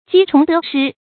注音：ㄐㄧ ㄔㄨㄙˊ ㄉㄜˊ ㄕㄧ
雞蟲得失的讀法